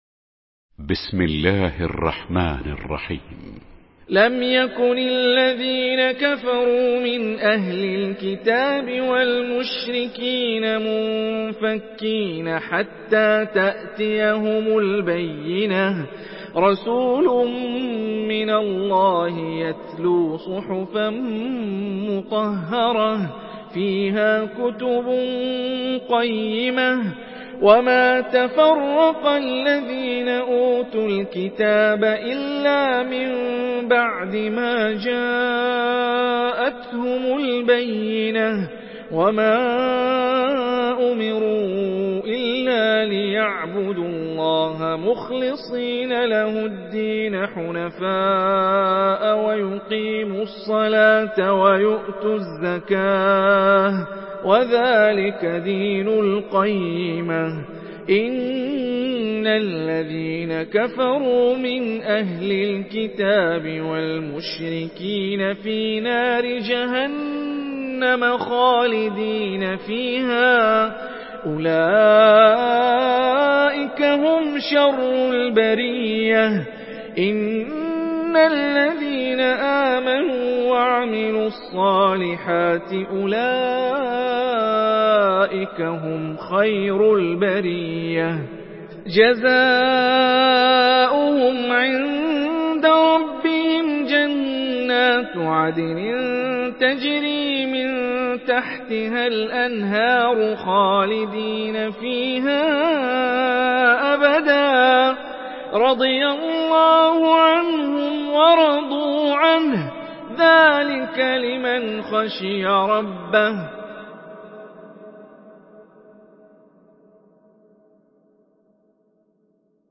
Surah Al-Bayyinah MP3 by Hani Rifai in Hafs An Asim narration.
Murattal Hafs An Asim